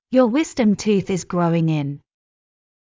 ﾕｱ ｳｨｽﾞﾀﾞﾑ ﾄｩｰｽ ｲｽﾞ ｸﾞﾛｳｲﾝｸﾞ ｲﾝ